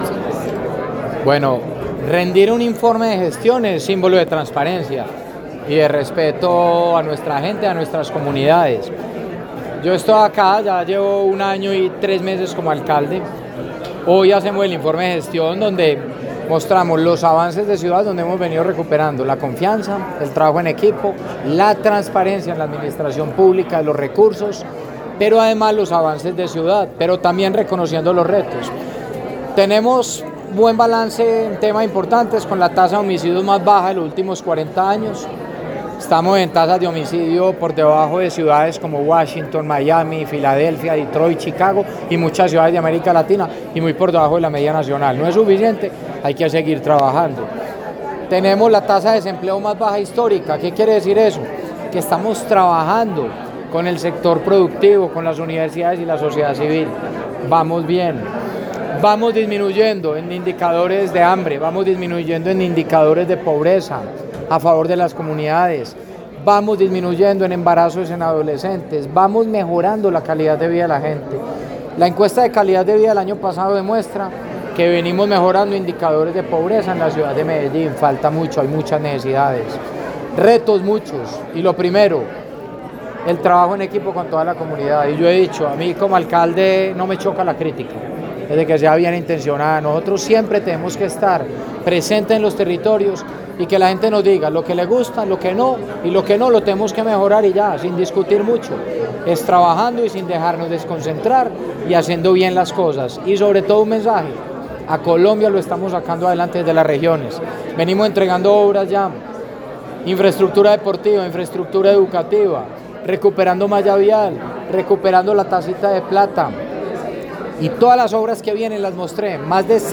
El alcalde Federico Gutiérrez Zuluaga, en la audiencia pública de rendición de cuentas ante el Concejo de Medellín.
Palabras de Federico Gutiérrez Zuluaga, alcalde de Medellín